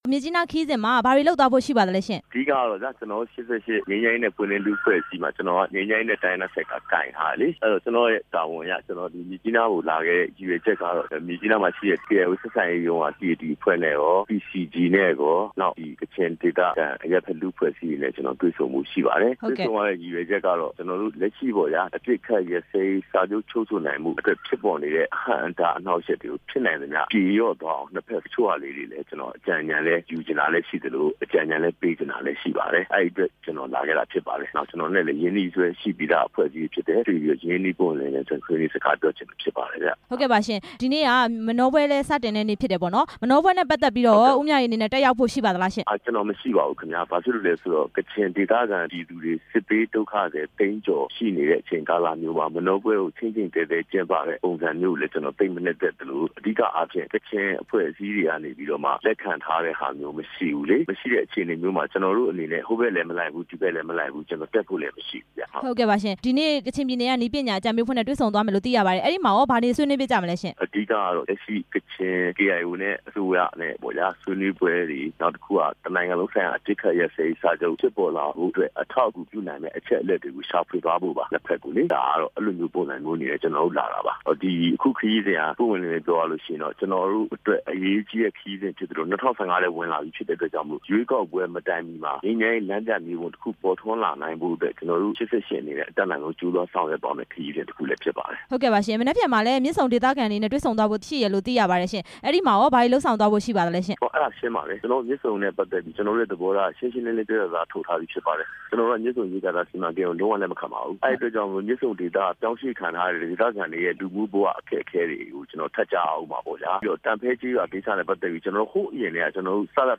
မေးမြန်းချက်